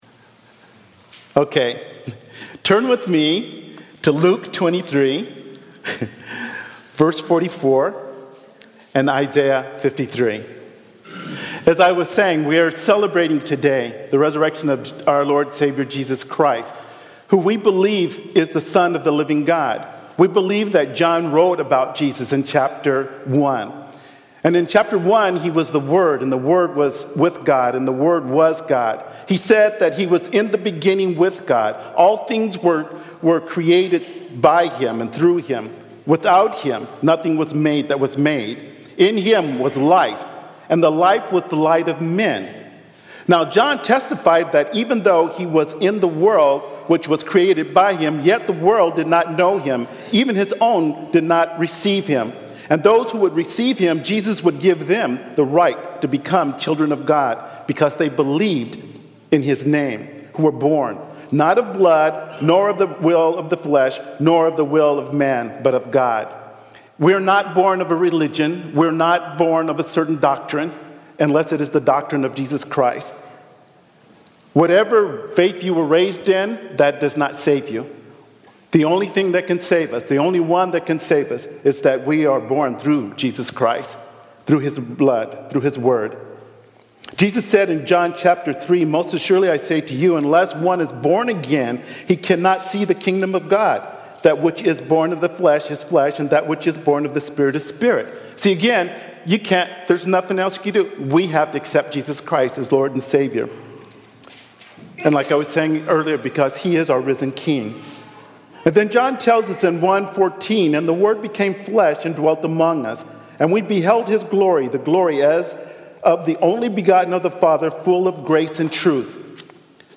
Easter Passage: Isaiah 53:1-10, Luke 23:44-24:1 Service Type: Sunday Morning « Jesus